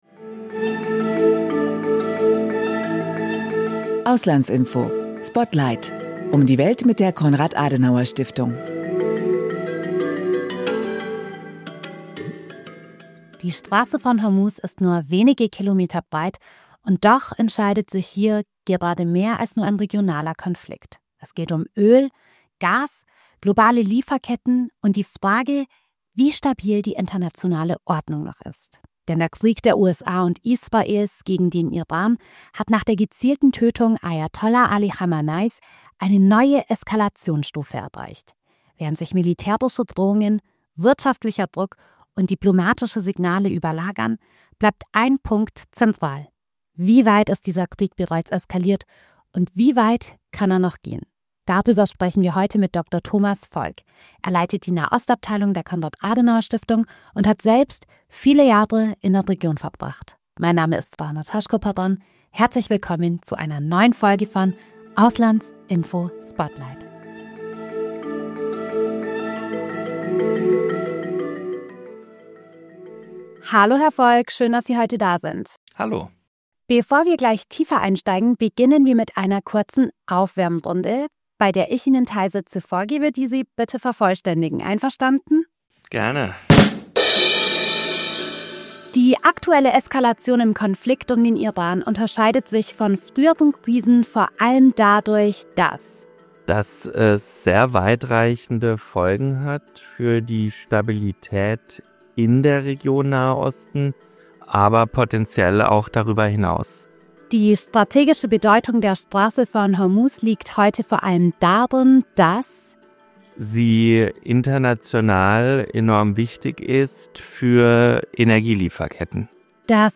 Und welche Risiken ergeben sich daraus für die Region – und für Europa? Darüber sprechen wir in dieser Folge unseres Podcasts mit dem Nahost-Experten